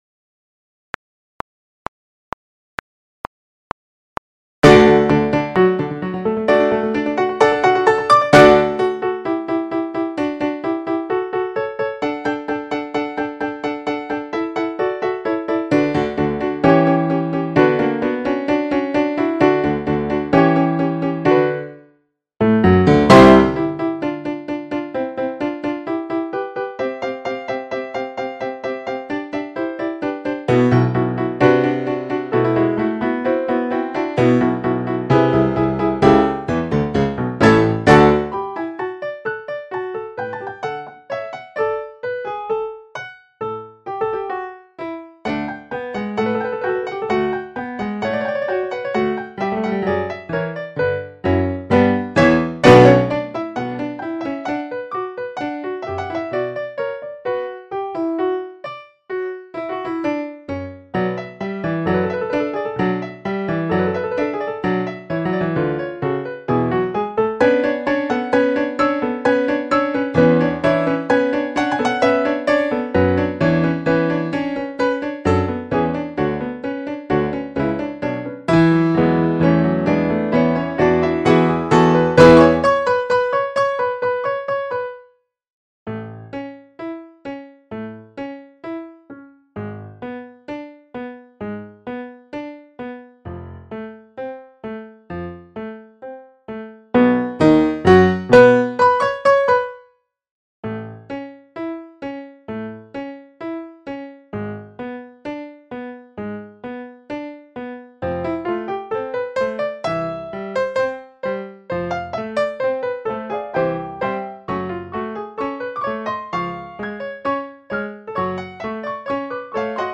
MIDI Practice Tracks:
Quarter note = 130